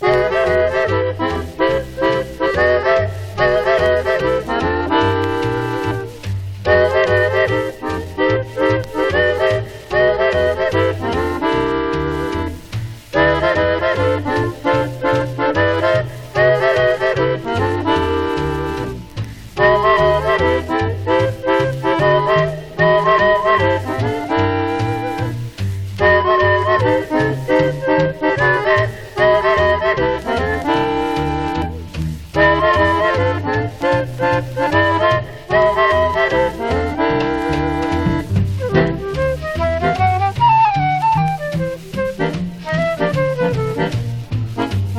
バランス良く軽妙洒脱なジャズを展開。
Jazz　USA　12inchレコード　33rpm　Mono